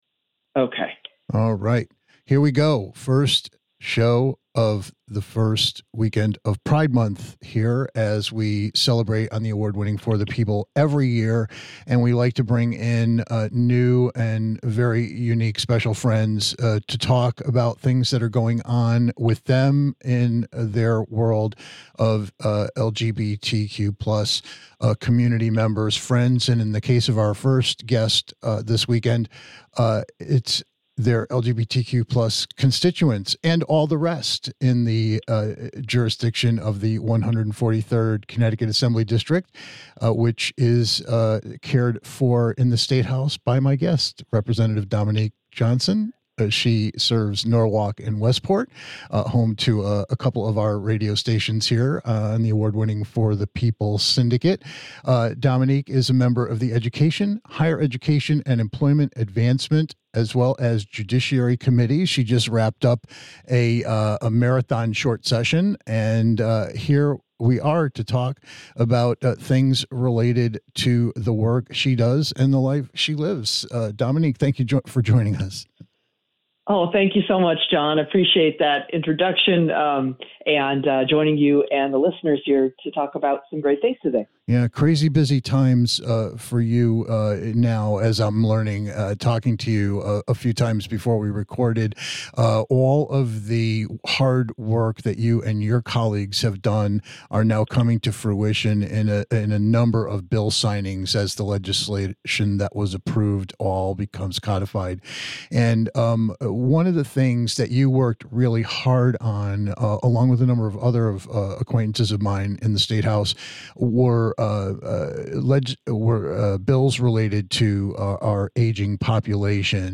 June is here - and that means it's Pride Month - so listen in as we participate in an engaging and empowering conversation with Connecticut State Rep Dominique E. Johnson - proudly serving Norwalk and Westport. After being out for over three decades, hear how Rep. Johnson advocates for some of the youngest and most marginalized members of our LGBTQ+ communities, and her views on how we can do better.